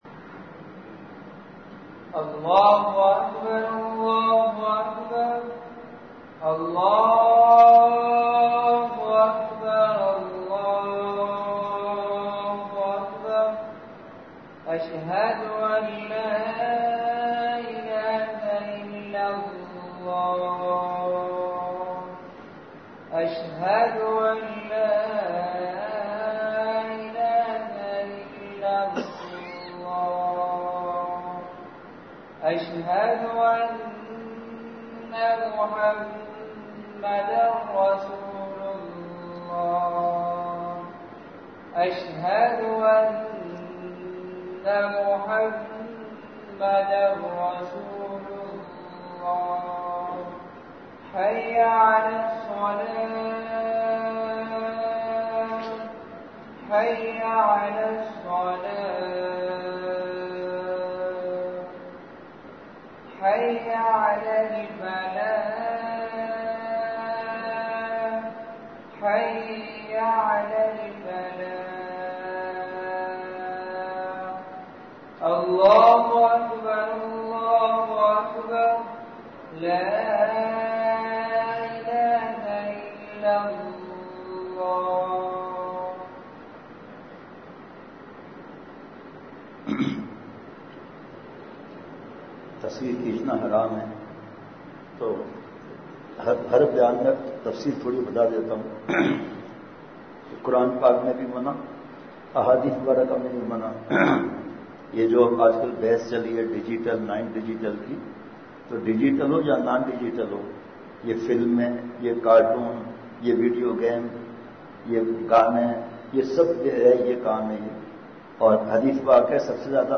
*بمقام:*حنفیہ مسجد کالونی نمبر ۲خانیوال
*جمعہ بیان*